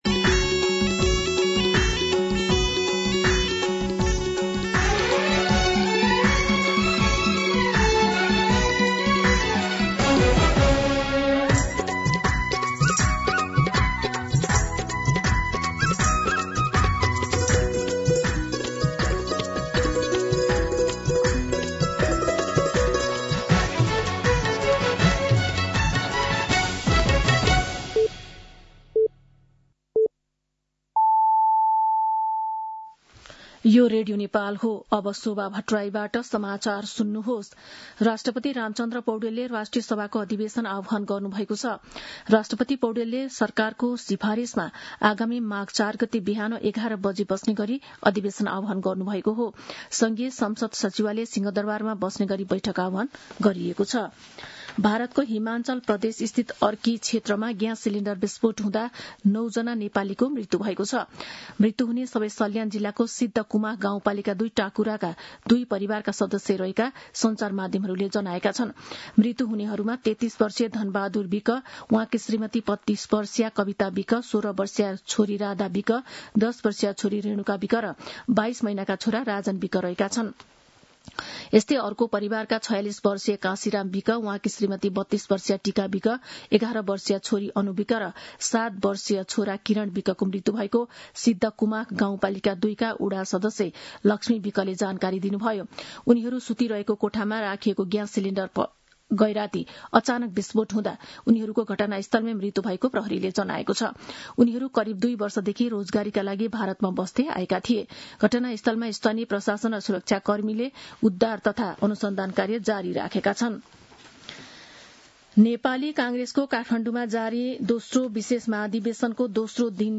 मध्यान्ह १२ बजेको नेपाली समाचार : २९ पुष , २०८२